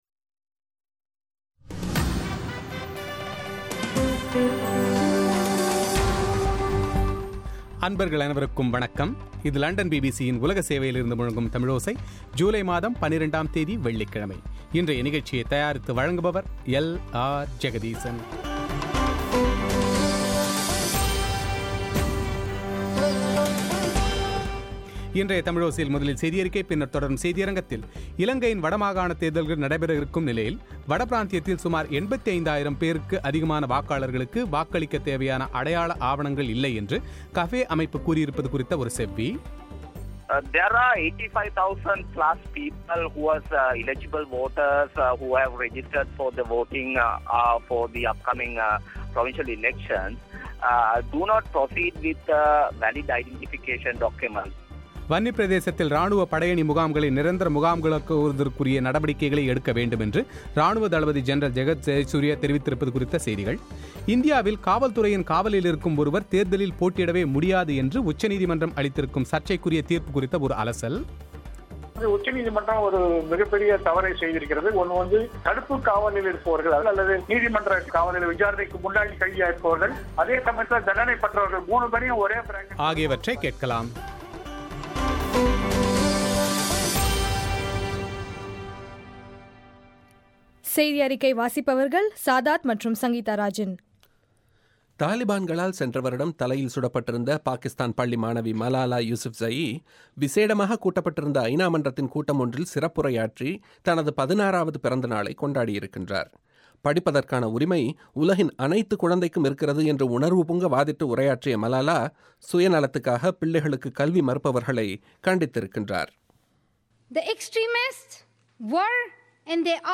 இலங்கையில் மாகாணசபைத் தேர்தல்கள் நடைபெறவுள்ள வட பிராந்தியத்தில் சுமார் 85 ஆயிரத்துக்கும் அதிகமான வாக்காளர்களுக்கு வாக்களிக்கத் தேவைான அடையாள ஆவணங்கள் இல்லை என்று கஃபே அமைப்பு கூறுயிருப்பது குறித்த செவ்வி